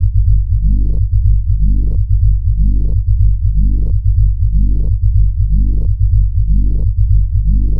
• tech house bass samples - Gm - 123.wav
tech_house_bass_samples_-_Gm_-_123_bn3.wav